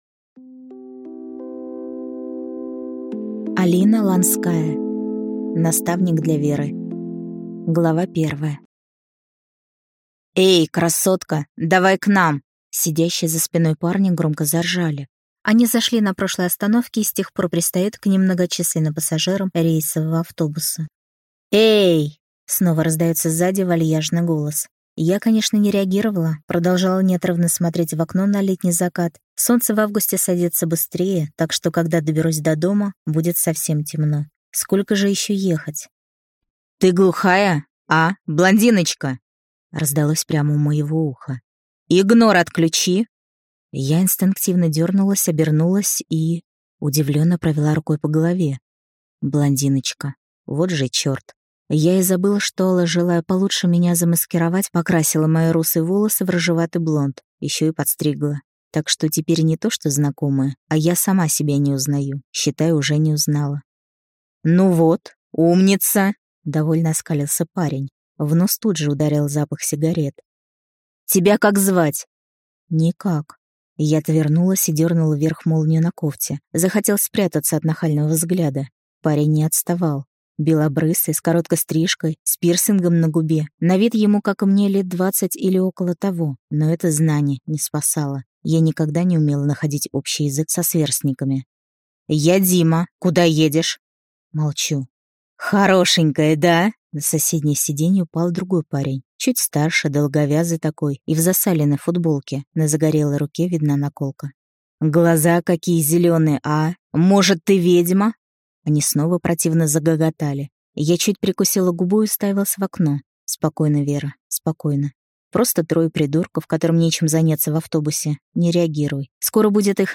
Аудиокнига Наставник для Веры | Библиотека аудиокниг